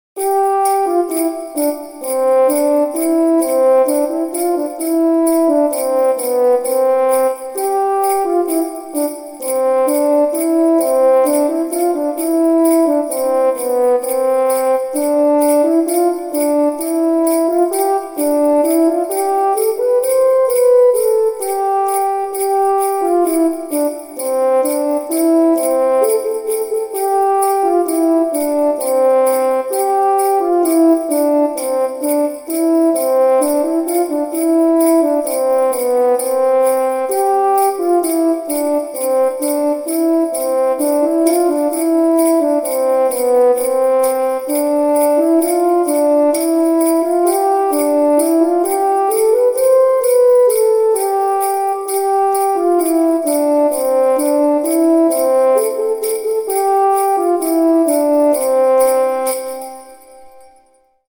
French Horn Arrangement – Festive Holiday Music
Genres: Classical Music
Tempo: 130 bpm